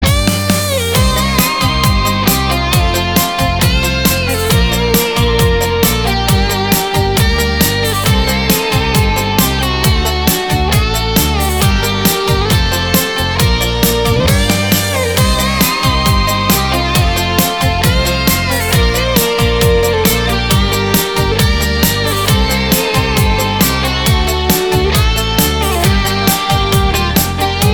• Качество: 320, Stereo
гитара
красивые
без слов